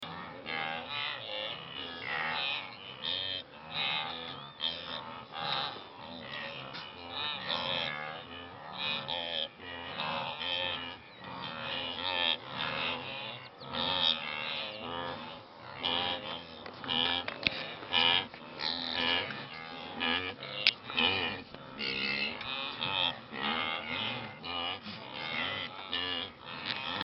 What was revealed had already been announced by the intense noise that we were already hearing as thousands of wildebeest bleating, moaning and snorting.
We also heard zebras barking and braying as they were also there mixed with the wildebeests, sharing their grazing.
the-sounds-of-the-wildebeests-copy.mp3